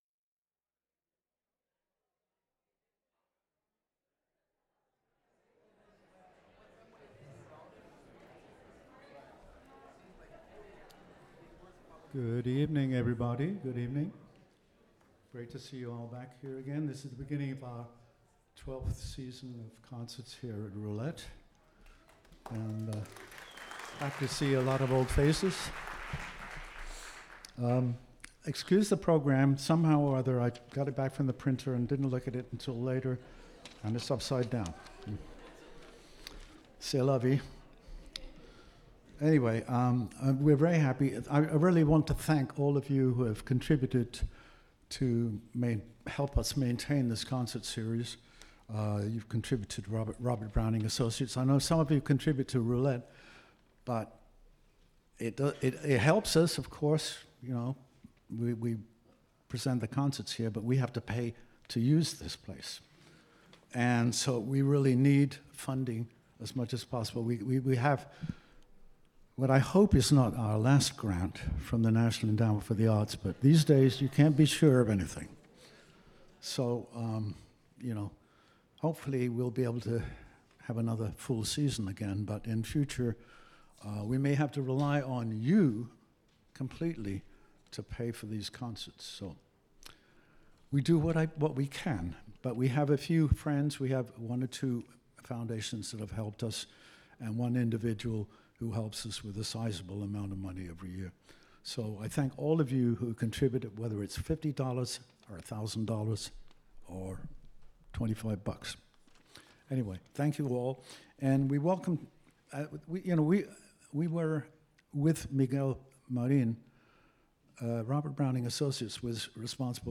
flamenco
guitar
percussion
palmas